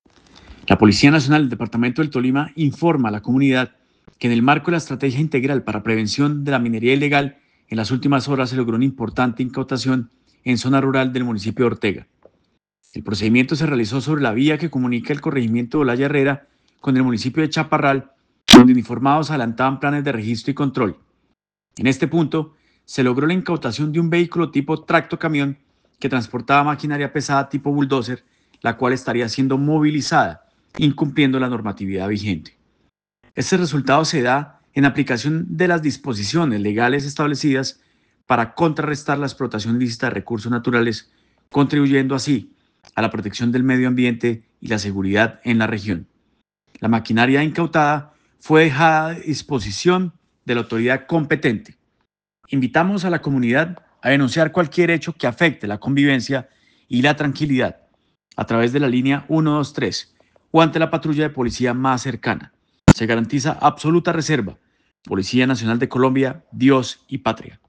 Audio Coronel John Anderson Vargas Izao Comandante Departamento de Policía Tolima.